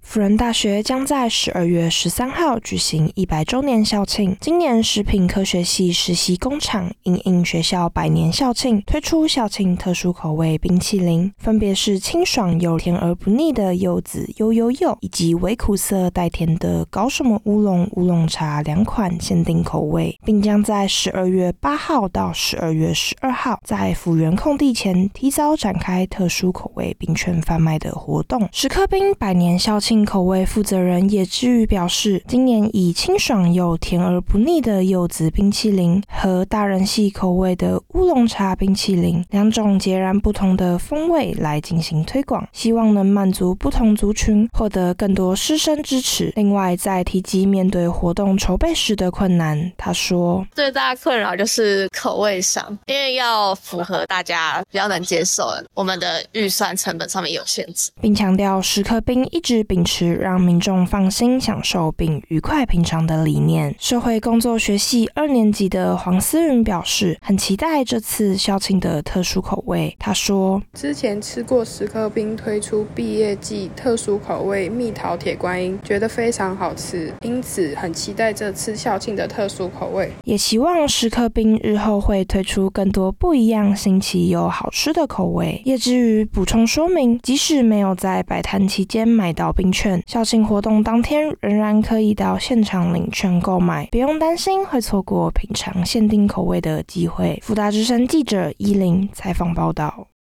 新聞話帶222_mixdown.wav